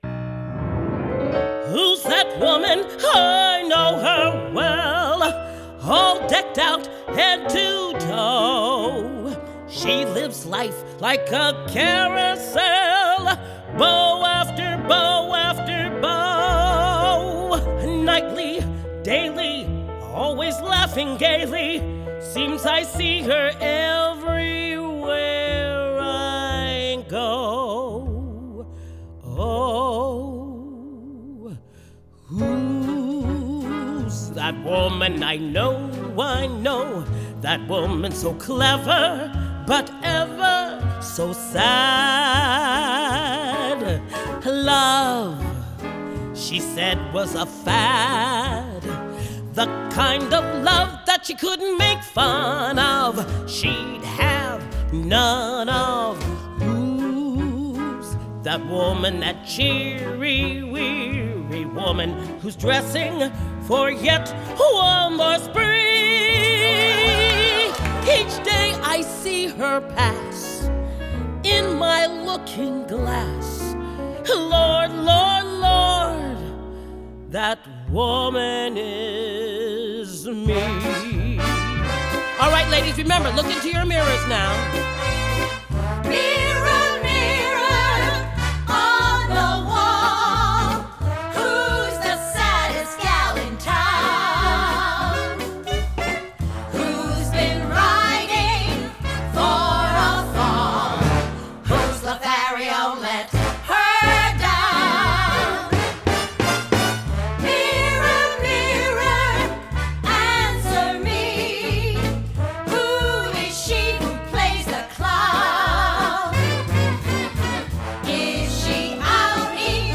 Her potent voice is hard not to remember.